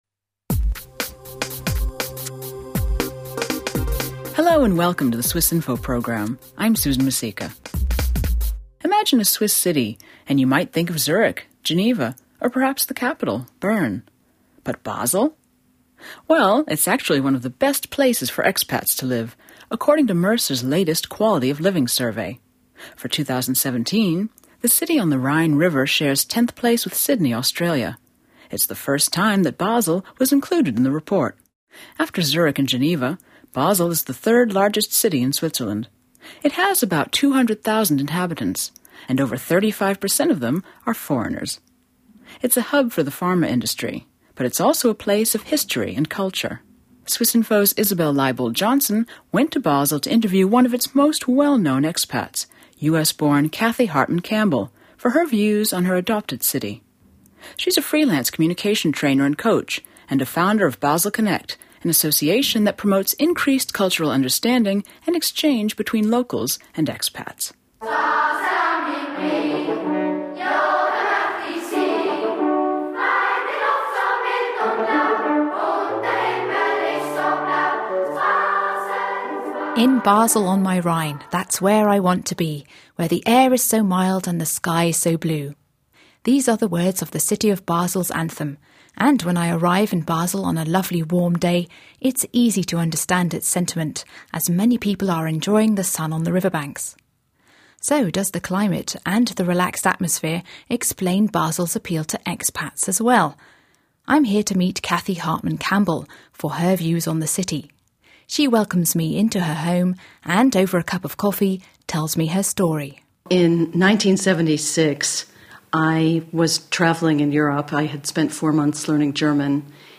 An American describes living in Basel for 35 years.